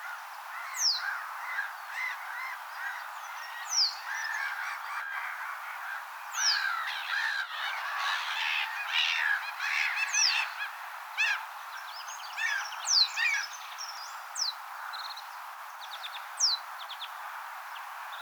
pajusirkun ääntelyä
pajusirkun_aantelya.mp3